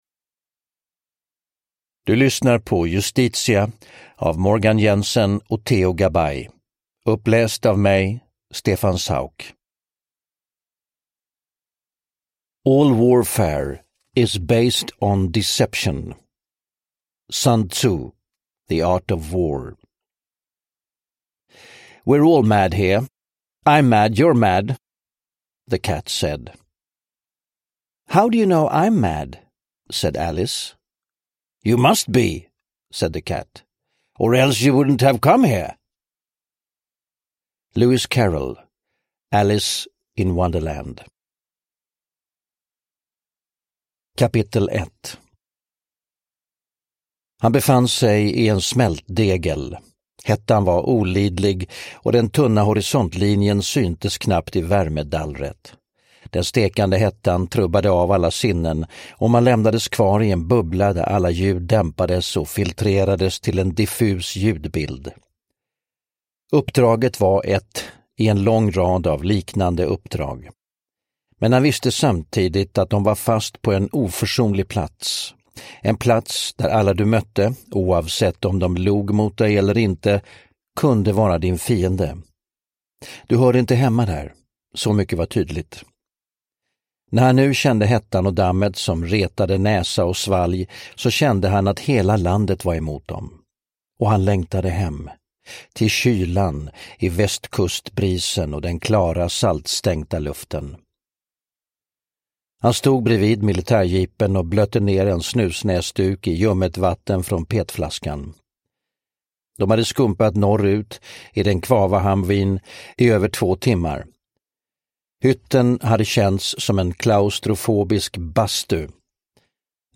Justitia (ljudbok) av Theo Gabay